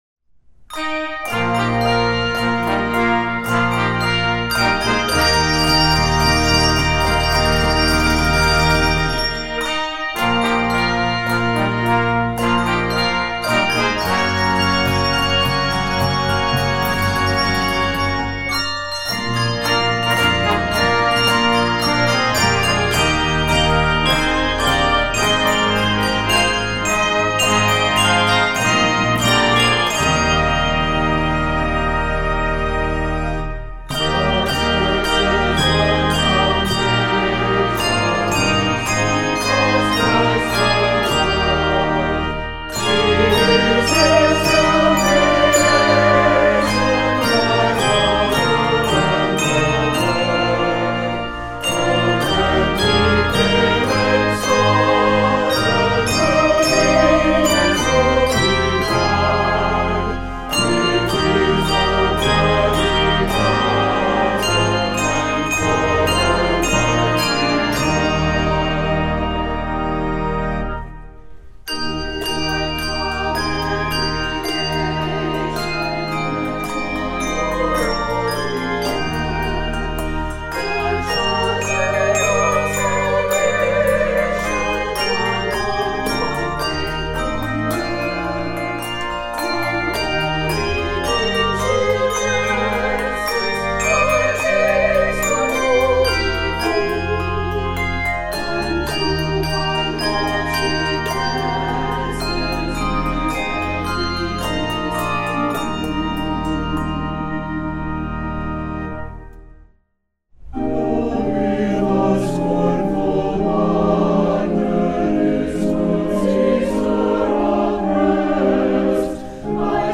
Key of Eb Major.